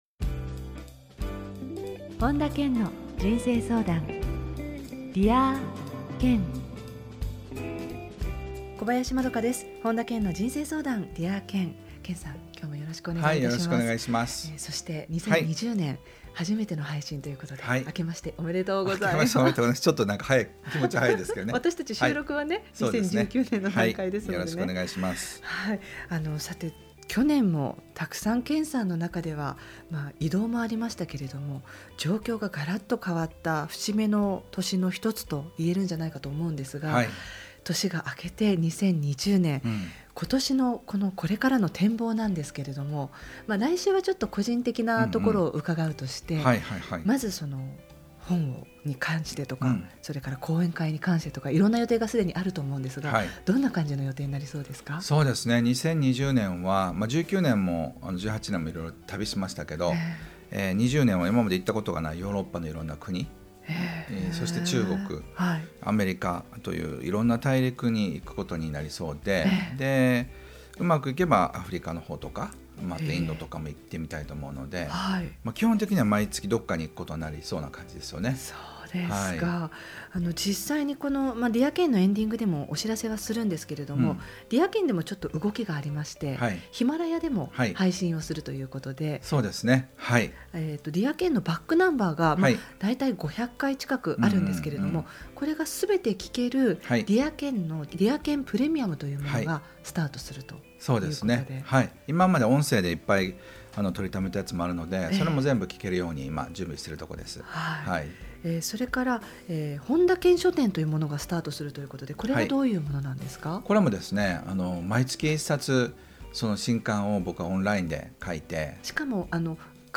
本田健の人生相談 ～Dear Ken～ 傑作選 今回は 「『決めた未来』願望達成法 前半」をテーマに、本田健のラジオミニセミナーをお届けします。